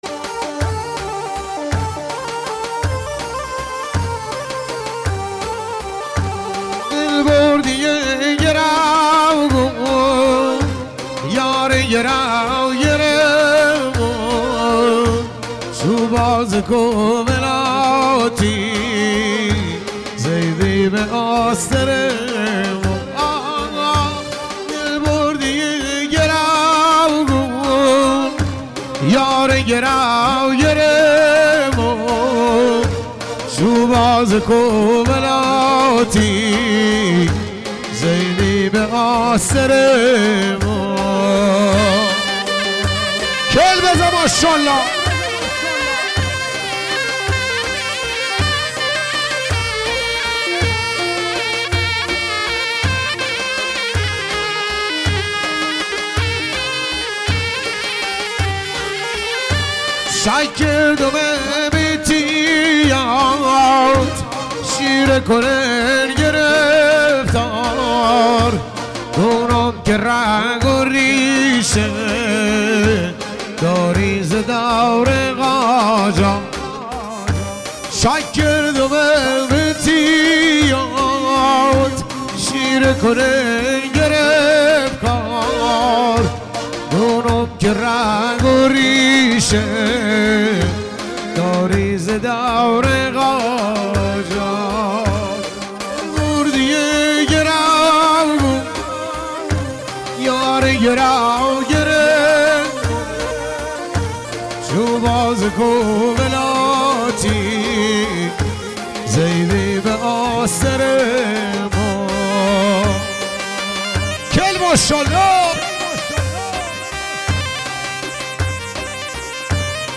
محلی لری